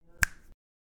Щелчок пальцами (1 раз)